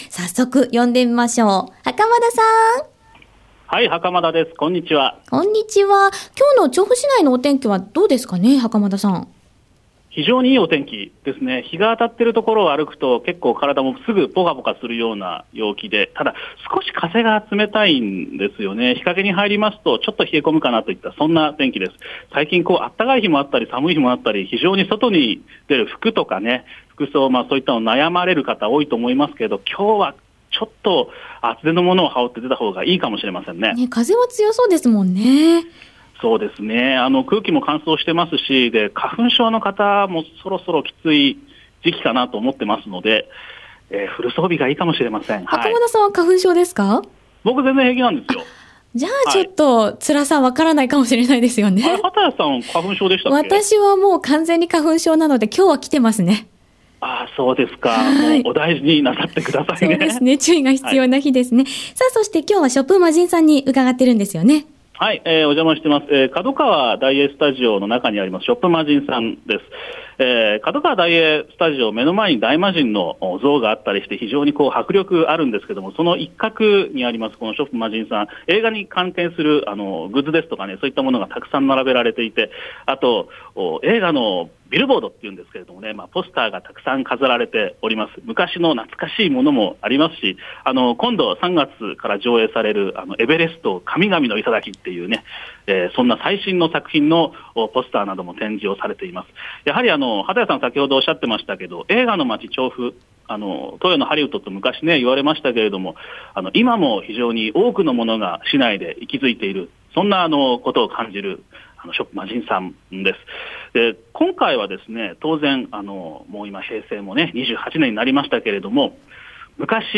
さて、3月1日（火）の街角レポートでは、調布市多摩川の角川・大映スタジオさんにあります、 SHOP MAJIN さんにお邪魔しました。